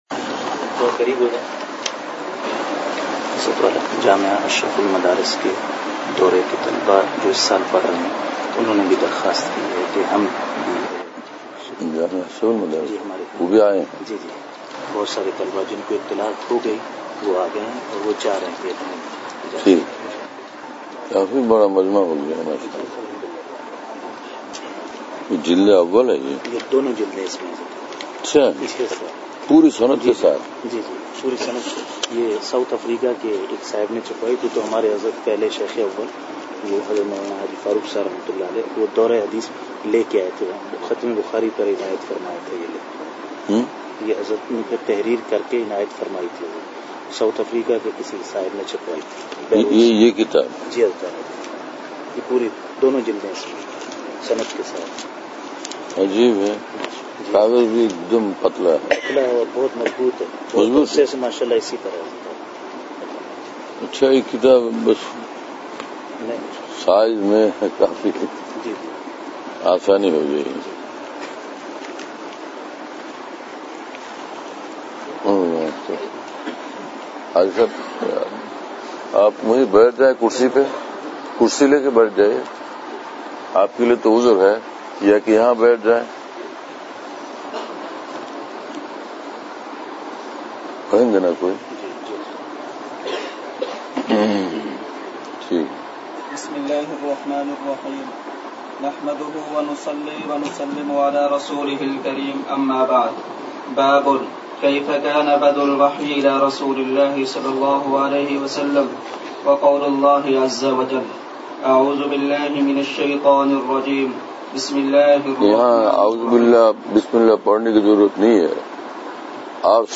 (۵۶: آڈیو) ظہر میں مختصر مجلس میں باتیں